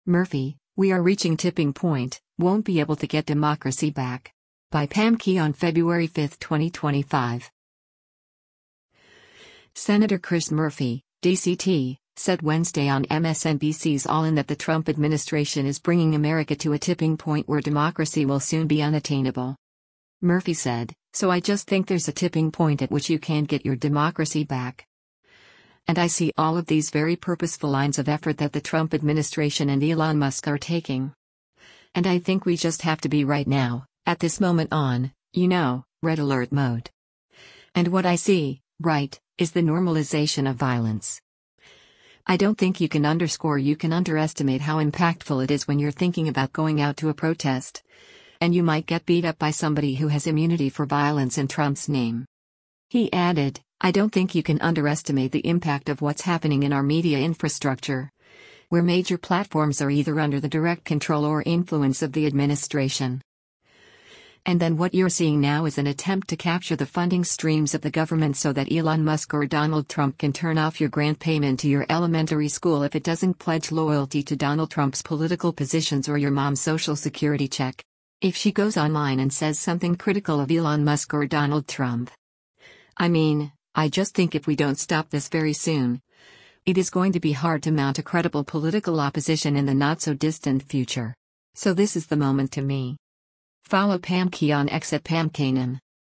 Senator Chris Murphy (D-CT) said Wednesday on MSNBC’s “All In” that the Trump administration is bringing America to a “tipping point” where democracy will soon be unattainable.